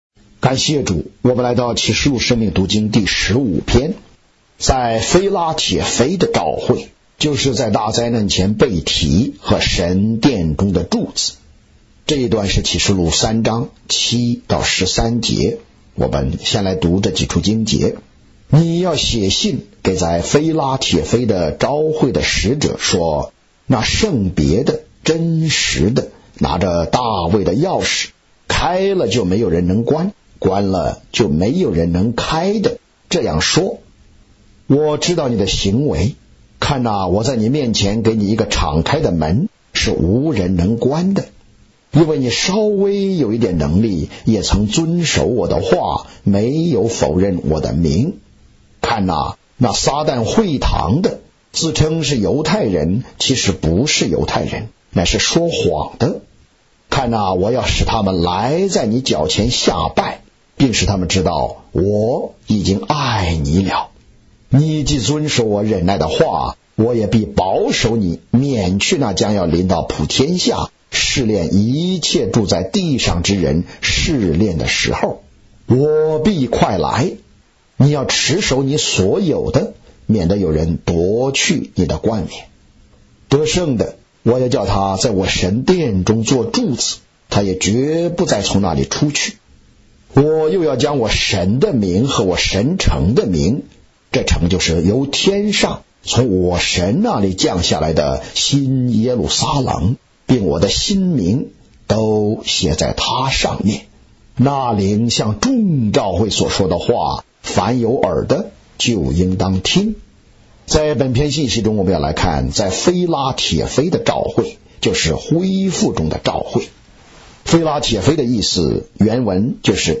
追求日期 3/25/2026👆啟示錄生命讀經 第十五篇全篇👆延伸讀經段落及註解：啟三7至13及註🔉語音播放生命讀經📃新約聖經恢復本(紙本)：P1245~P1248📃啟示錄生命讀經(紙本)：P228L2~P234L6